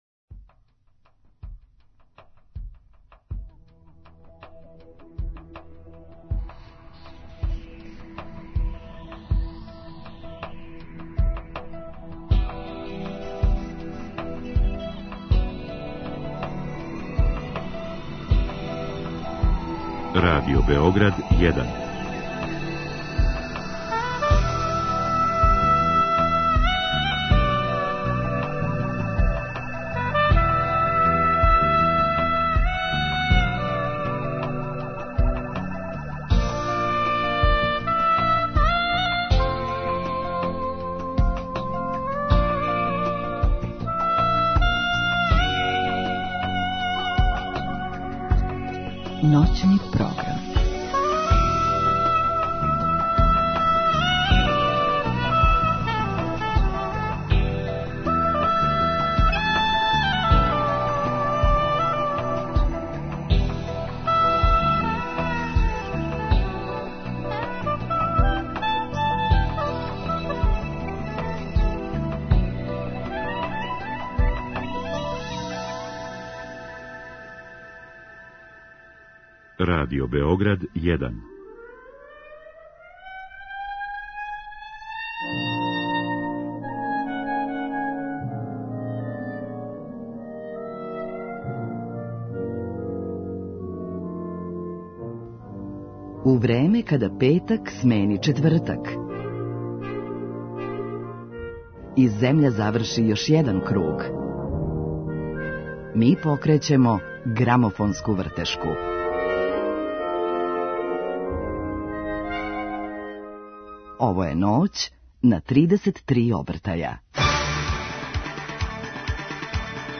Гости БендОвер.